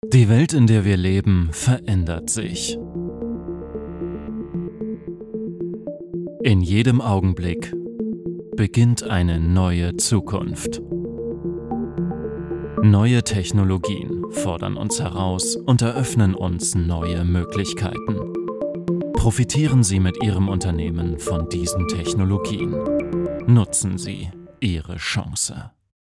flexible, junge, dynamische Stimme, diverse Figuren, Stimmen, Akzente, Dialekte perfekte Phonetik, abgeschlossene Schauspielausbildung groÃƒÅ¸es Interesse in beinahe allen Bereichen, FlexibilitÃƒÂ¤t und Disziplin
Sprechprobe: Industrie (Muttersprache):
Imagefilm - Technologie - Musik.mp3